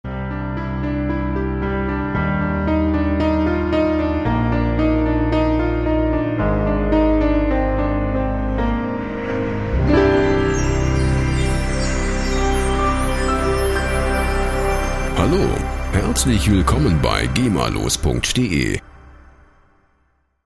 rechtefreie Audio Logos
Musikstil: Audio Logo
Tempo: 60 bpm